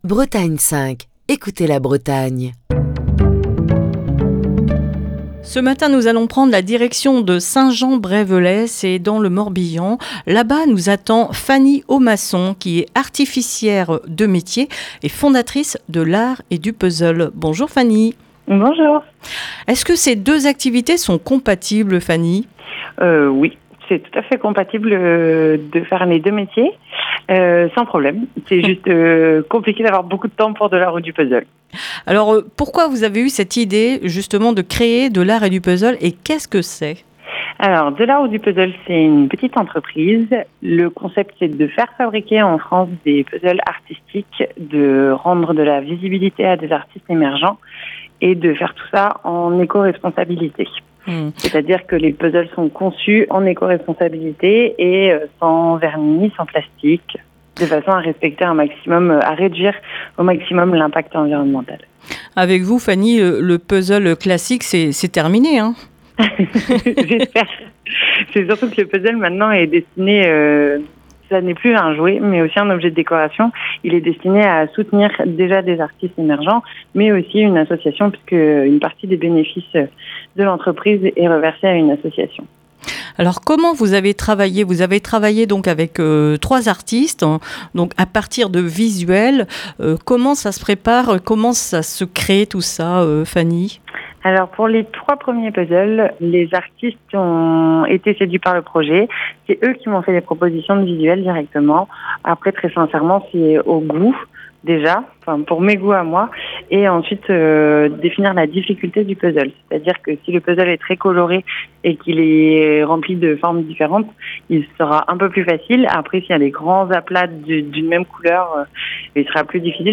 Ce mardi dans le coup de fil du matin